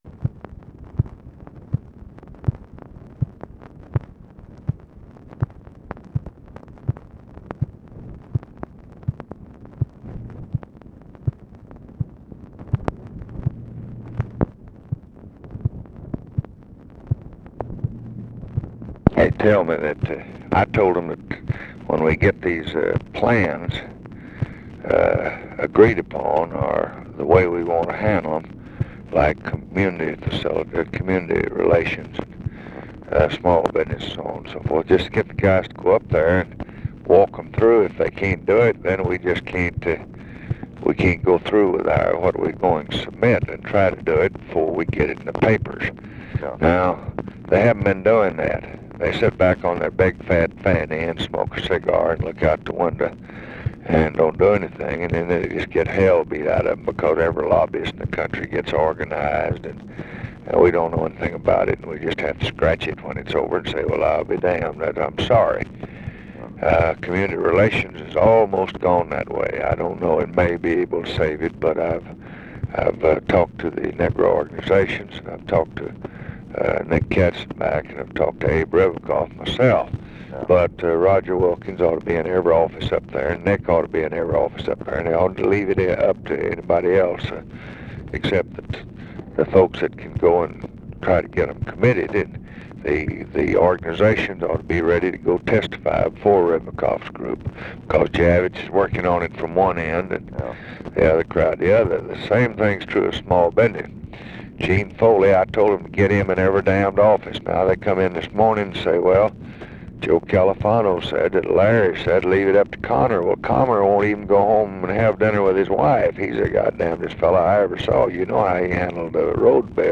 Conversation with LARRY O'BRIEN, February 5, 1966
Secret White House Tapes